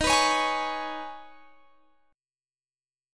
Eb7sus4#5 chord